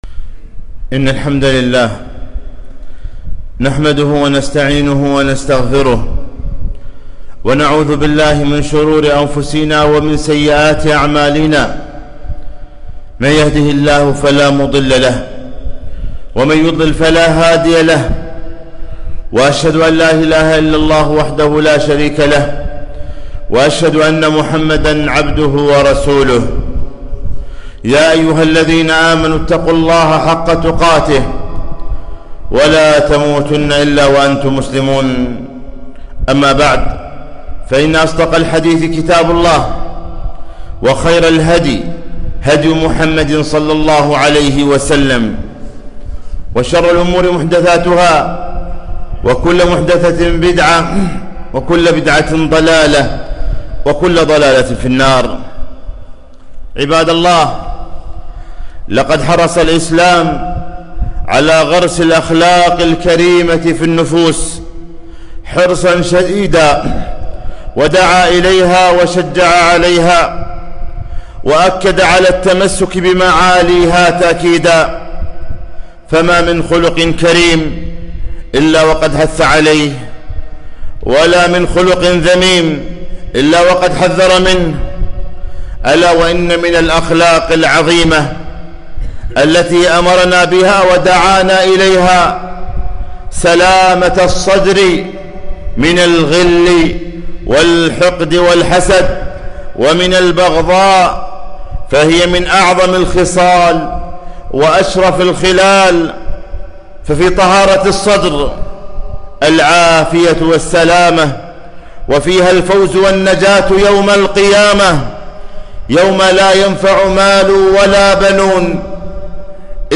خطبة - سلامة الصدور